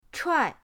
chuai4.mp3